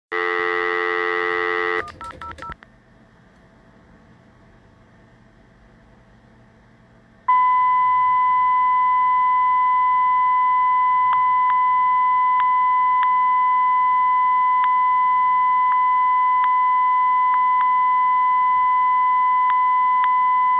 For some reason, using G722 codec caused clicking to be added to the call, but this does not happen with G711.
Clicking sounds "random" to the ear and does not have a steady rhthym.
03-Tone_-_PlayTones.mp3